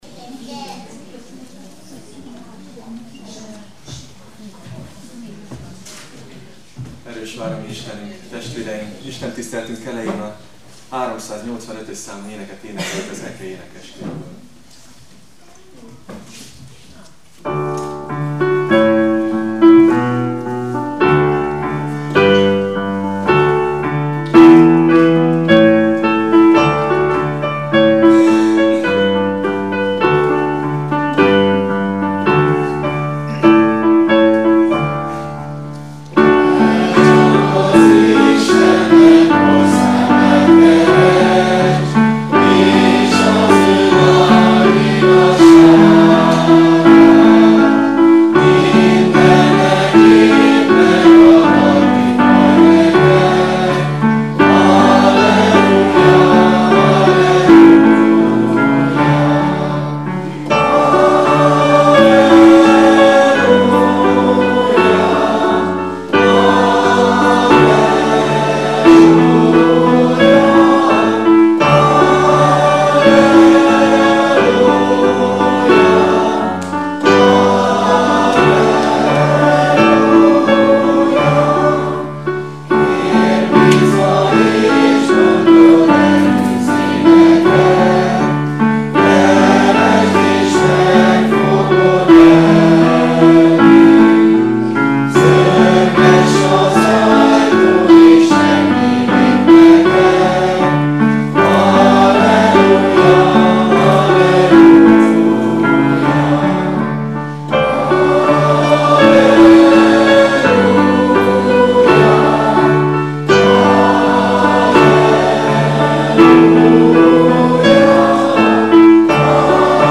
Karácsonyi Istentisztelet december 25.